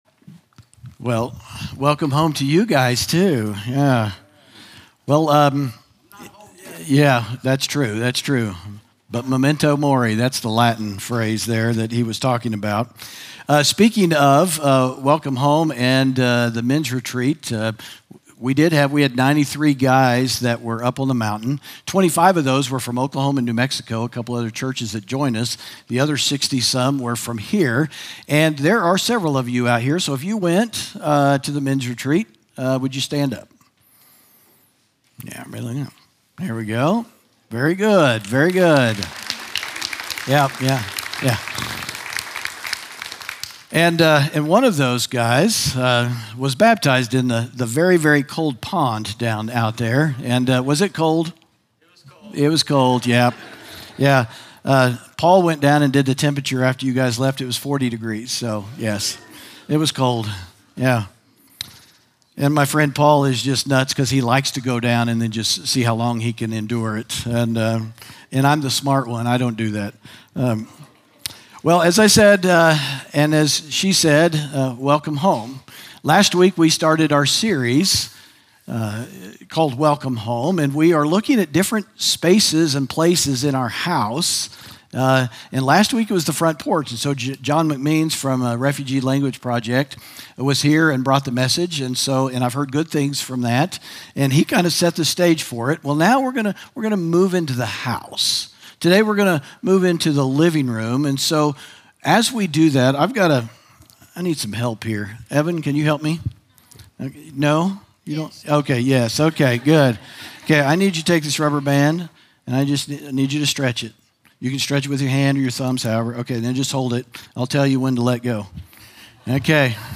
sermon audio 0504.mp3